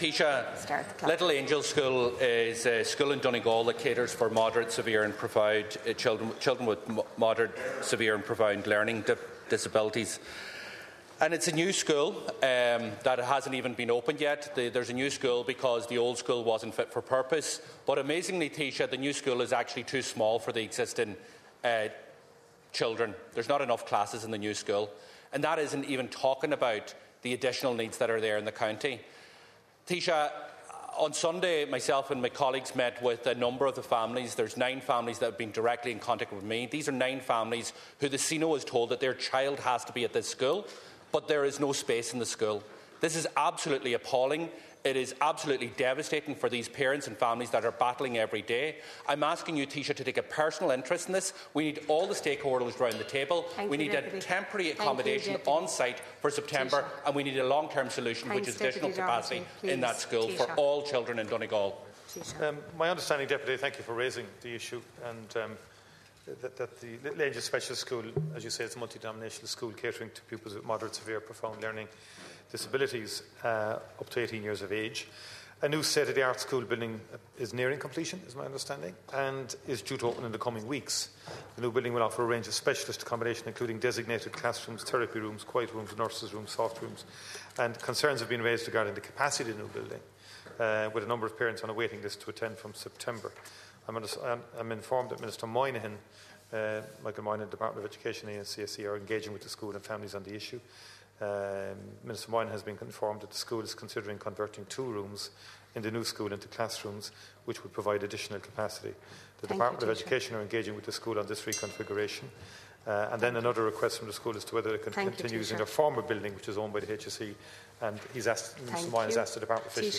In the Dail last evening, Donegal Deputy Pearse Doherty said the situation families are facing is appalling and devastating for them.
In response, Micheal Martin says a number of proposals to increase capacity are being considered: